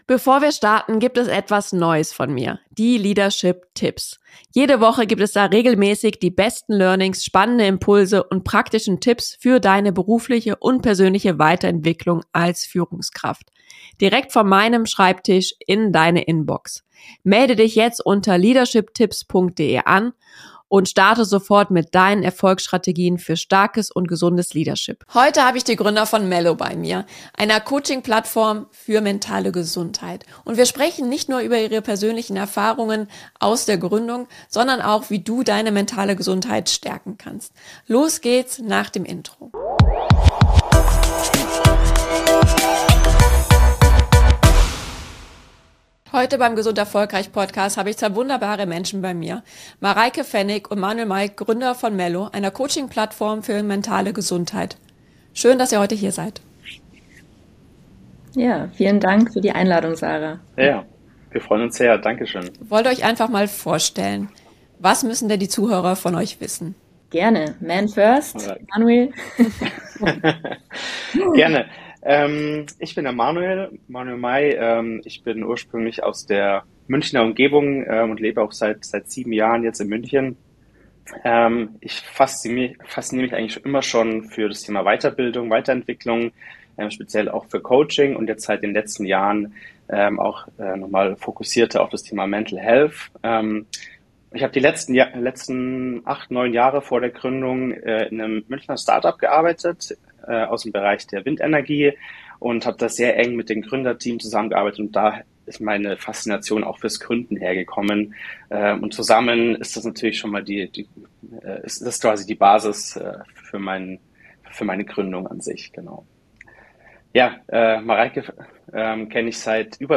Nr. 50 - Warum Coaching in deinem Team einen Unterschied bewirkt - Interview